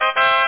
HERZ.mp3